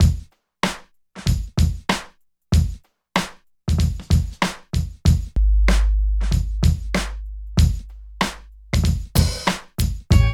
43 DRUM LP-R.wav